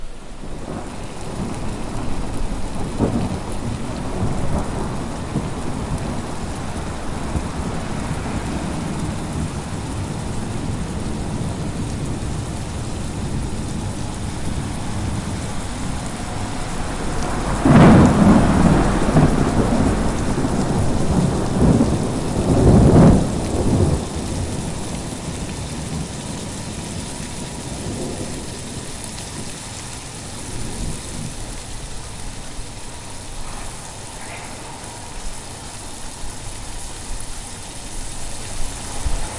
描述：在罗马下雨
Tag: 雨水 环境